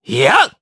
Zafir-Vox_Attack3_jp.wav